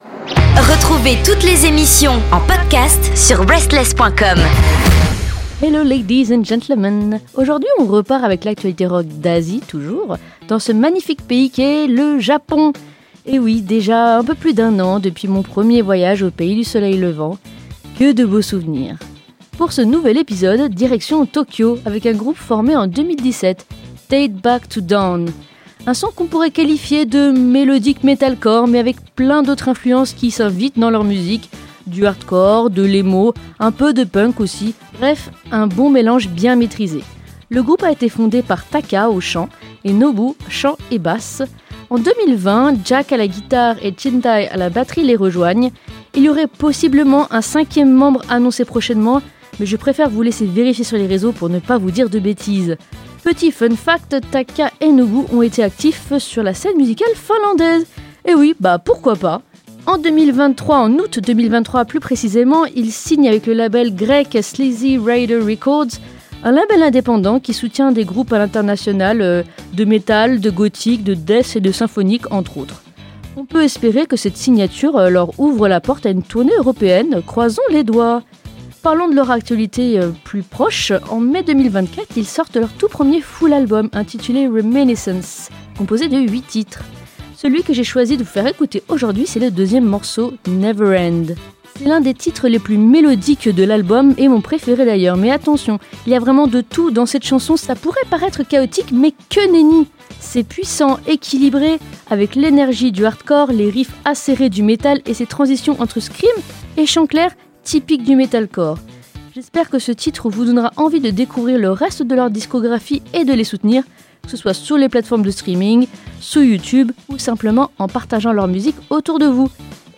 Aujourd’hui, on vous emmène au Japon découvrir un groupe basé à Tokyo depuis 2017 : DATE BACK TO DAWN. Le groupe évolue dans un registre melodic metalcore, avec des influences hardcore, emo et punk.
Un metal mélodique énergique, à la croisée des genres, pour une scène japonaise toujours en pleine effervescence.